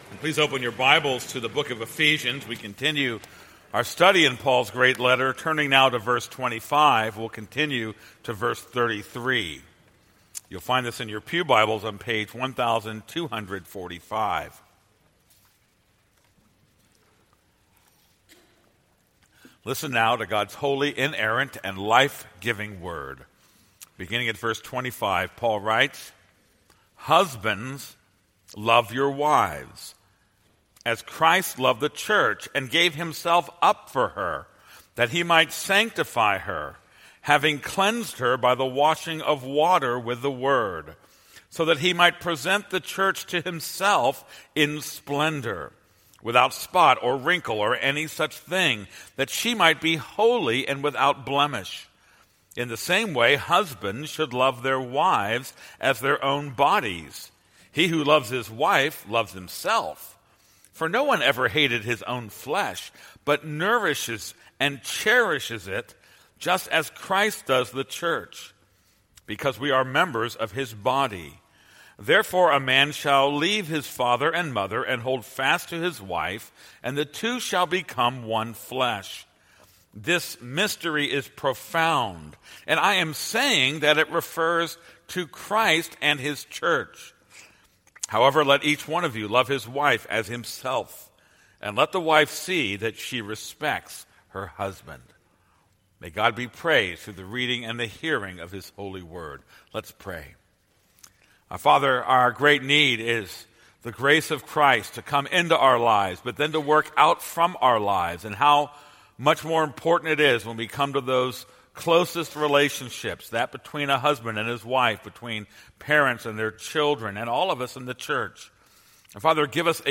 This is a sermon on Ephesians 5:25-35.